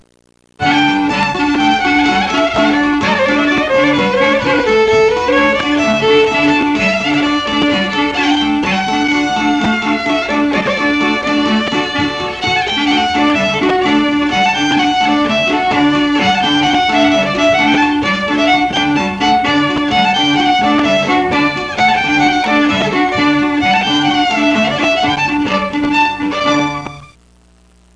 folk.mp3